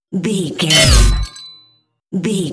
Dramatic hit metal electricity debris
Sound Effects
heavy
intense
dark
aggressive
hits